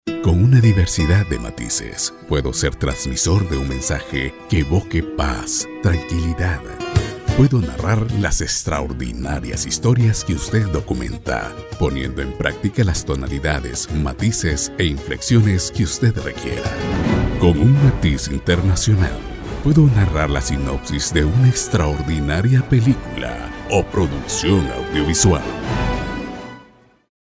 Sprecher spanisch, Lateinamerika.
spanisch Südamerika
Soy locutor hondureño y he desarrollado el dominio tonos altos, medios y bajos en mi voz y cuento con 15 años de experiencia ejerciendo locución comercial.